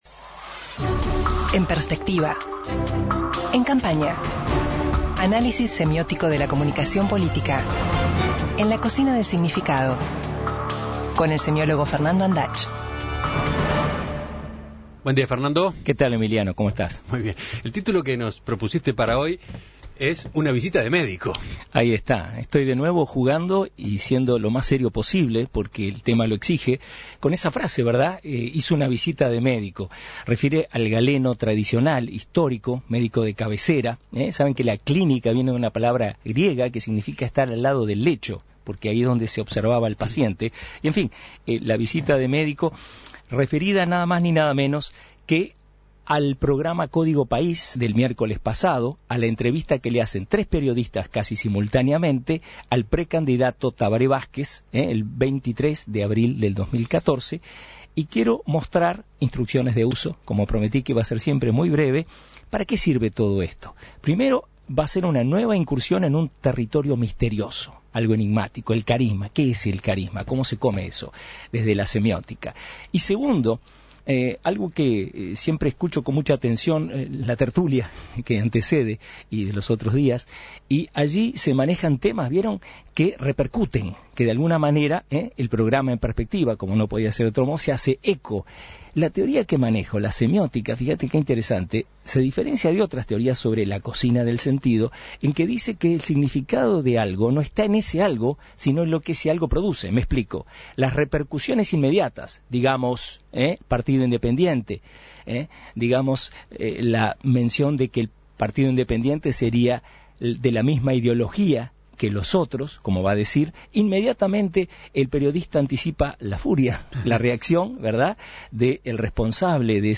(Audio entrevista a Tabaré Vázquez en Código País.)